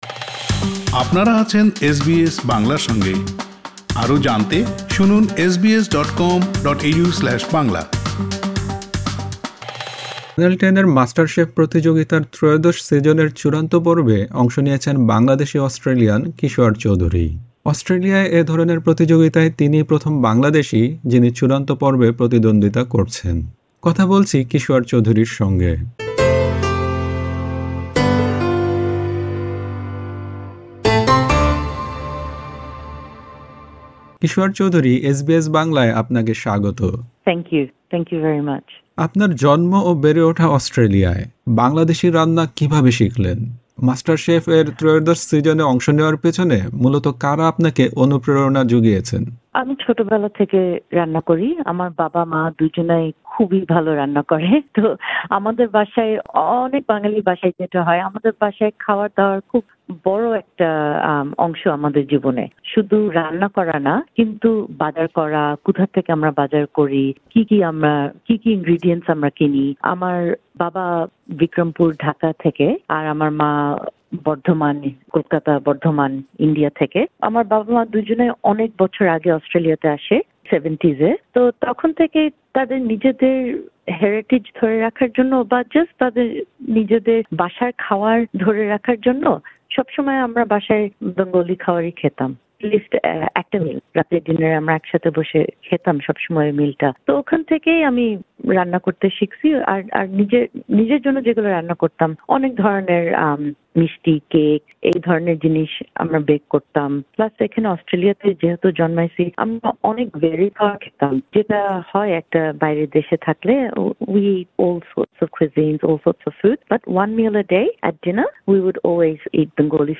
এসবিএস বাংলার সঙ্গে আলাপে তিনি বলেন, বাঙালি রান্না খুবই ইউনিক।